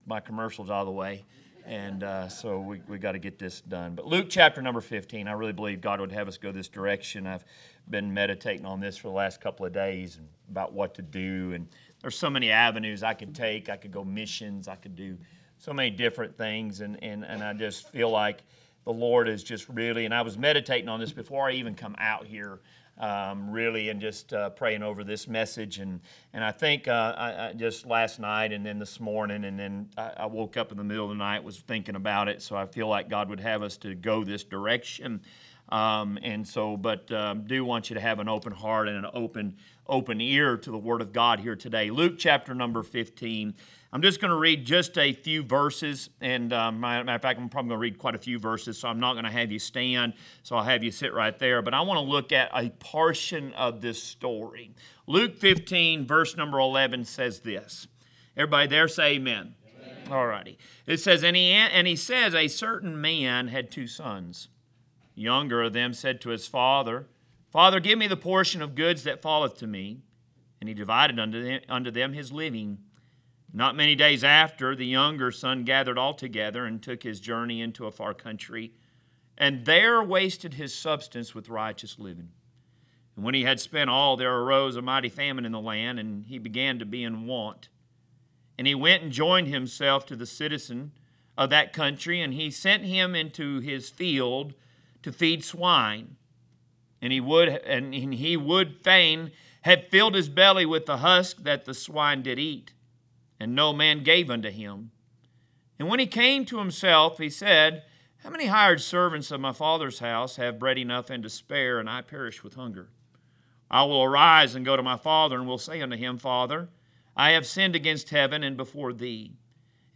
A message from the series "Out of Series." Luke ch. 15